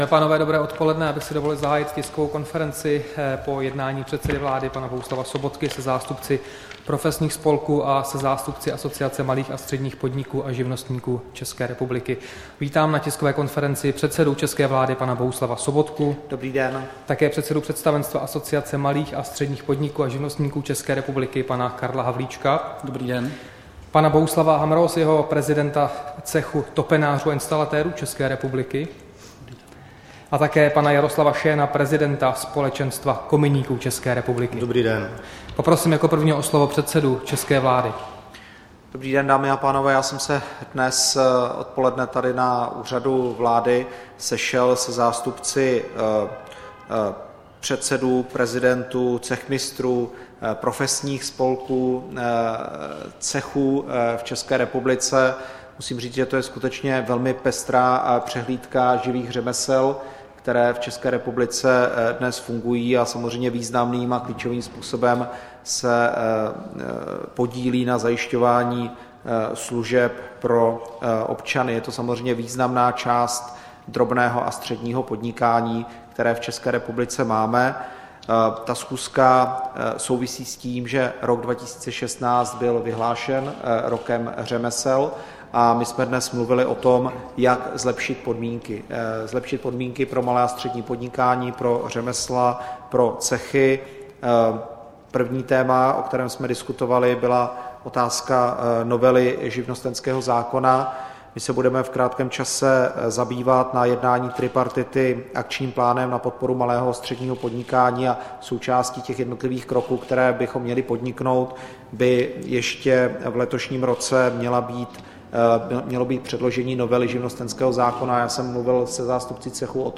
Tisková konference po setkání předsedy vlády se zástupci profesních spolků, 19. května 2016